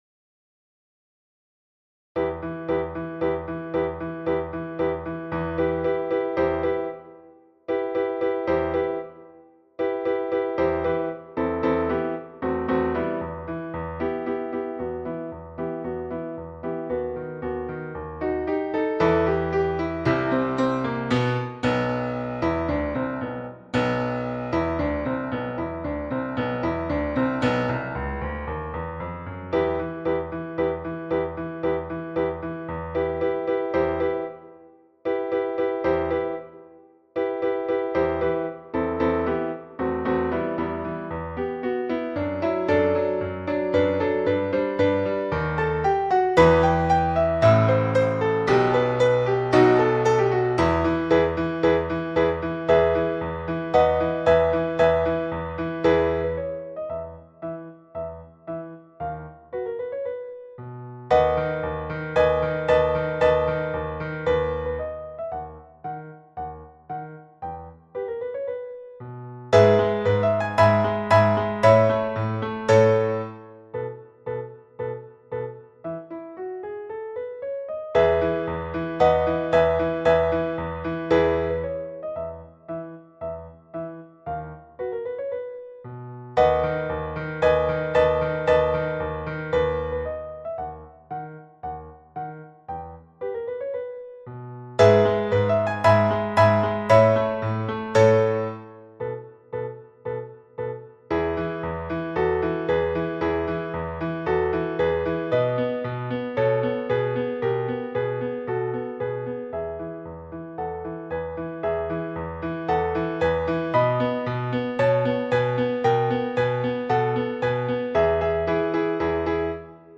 The Barber of Seville – piano à 114 bpm